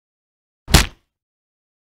Punch_Sound_Effect_Realistic